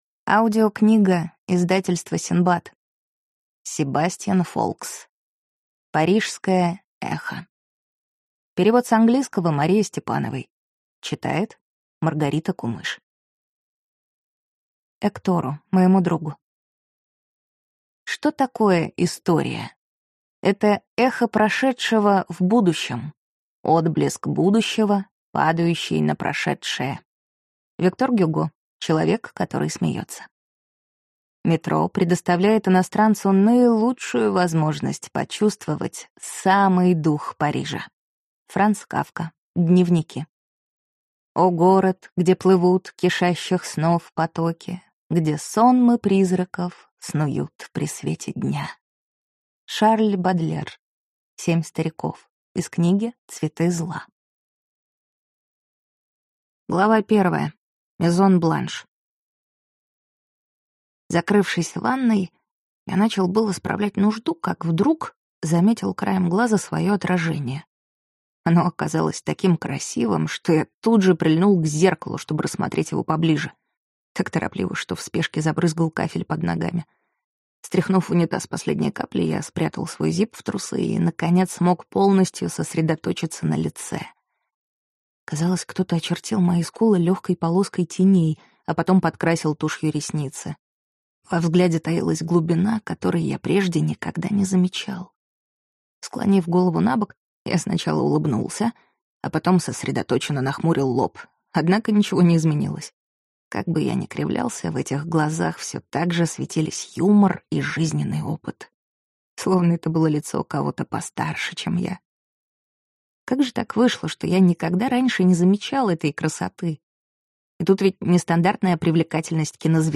Аудиокнига Парижское эхо | Библиотека аудиокниг